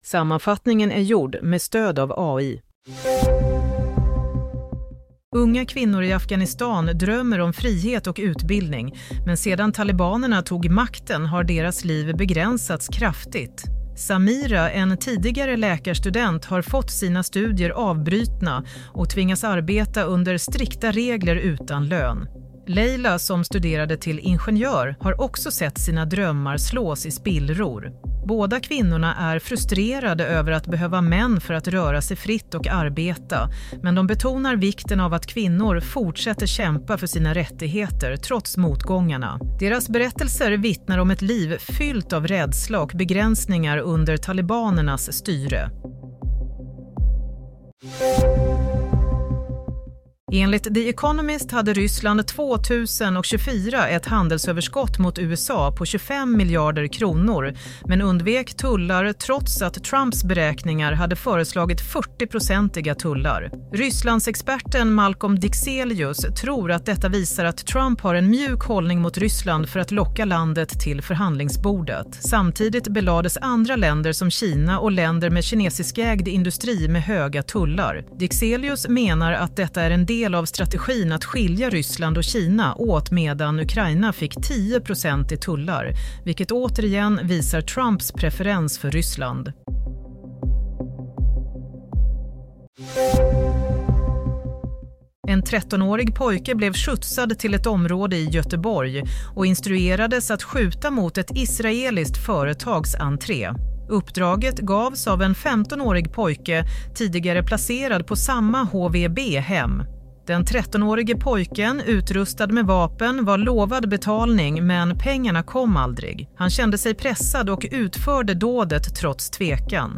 Nyhetssammanfattning - 6 april 16:00